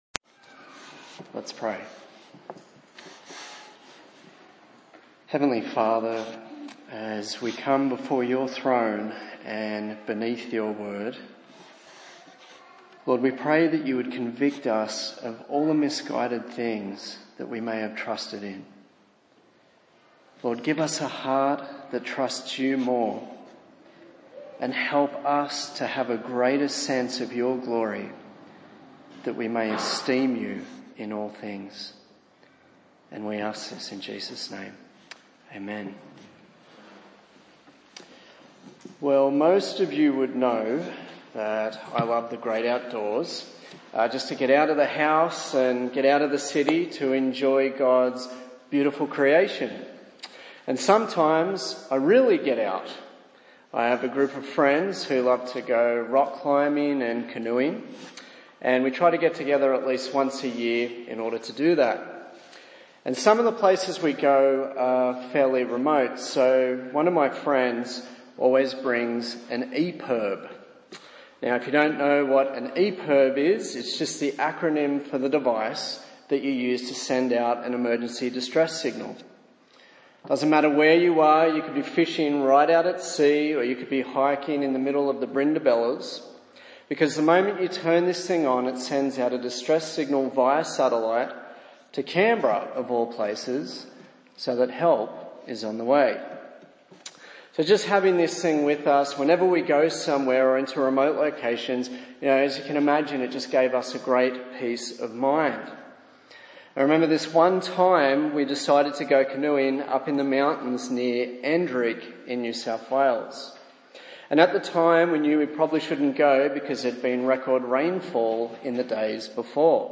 A sermon in the series on Isaiah